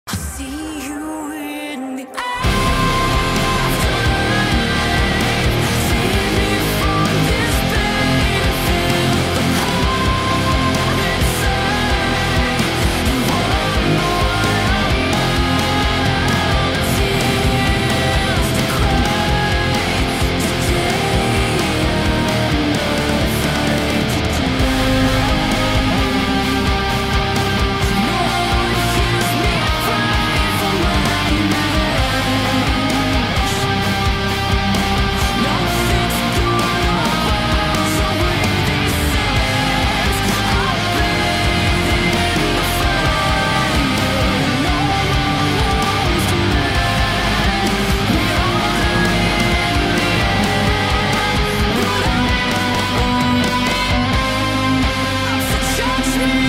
Also, I made up the bridge section.
electricguitar